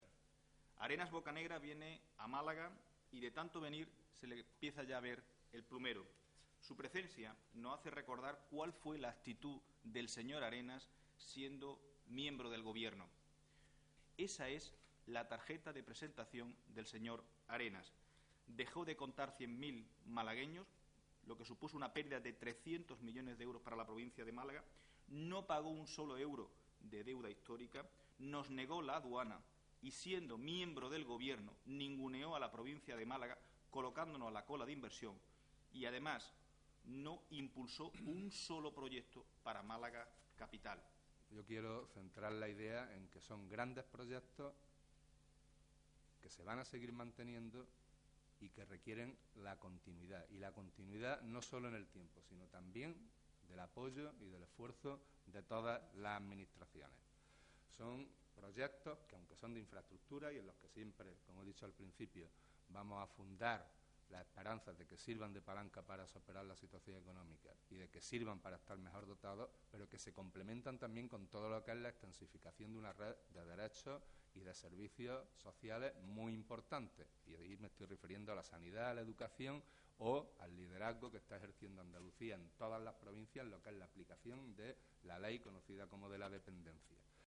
Ávila ha participado en una reunión con miembros de la Agrupación Municipal del PSOE y de la ejecutiva provincial y ha ofrecido una rueda de prensa con el secretario general de los socialistas malagueños